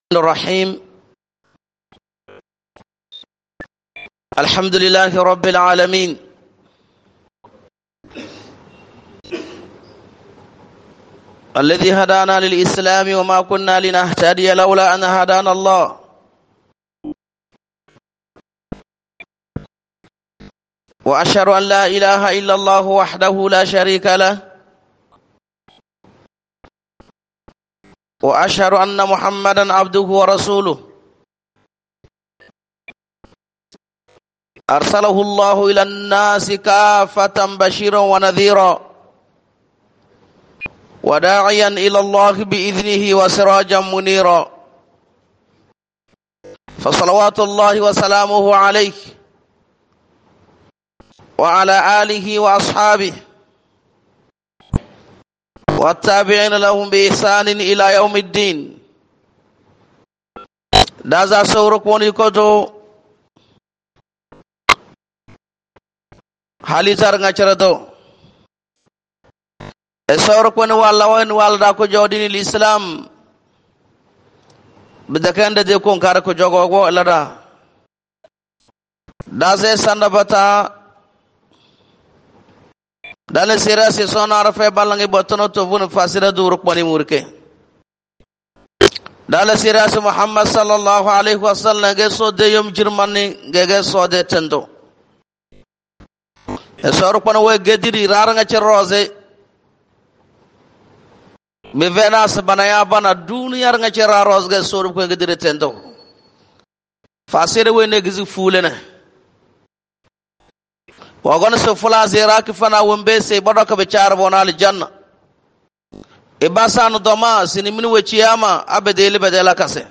PRECHE